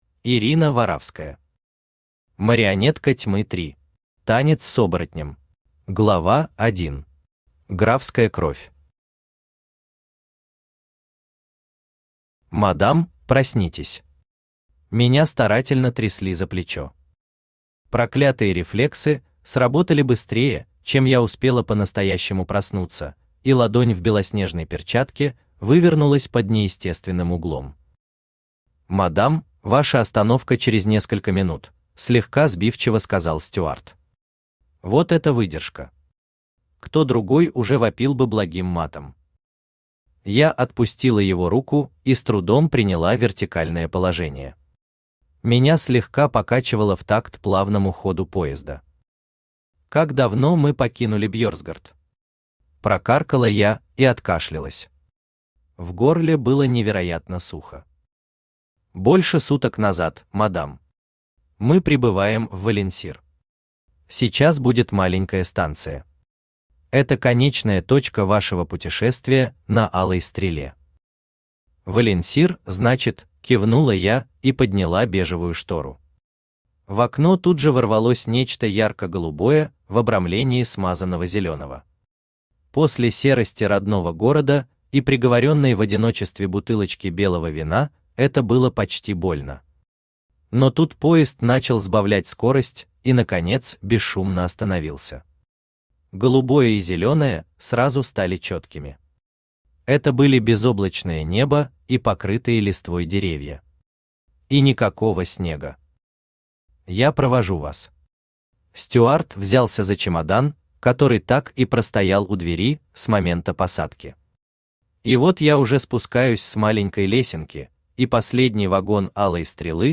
Танец с оборотнем Автор Ирина Варавская Читает аудиокнигу Авточтец ЛитРес.